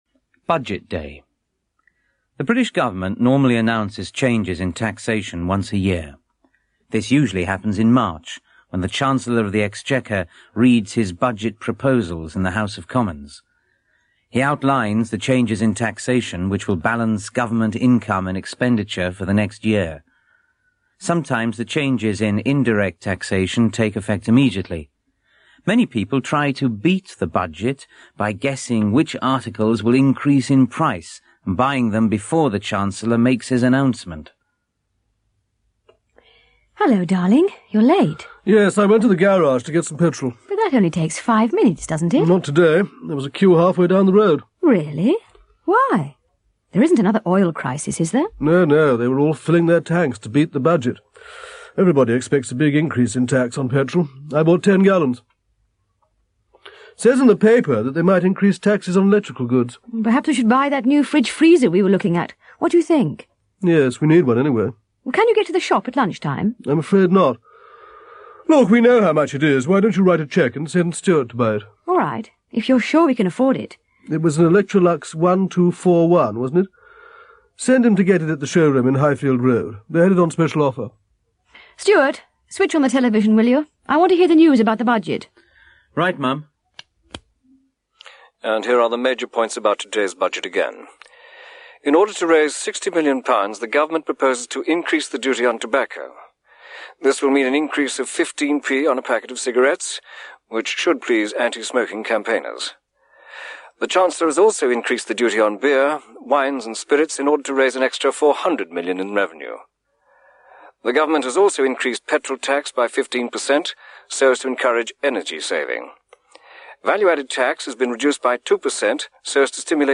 English conversation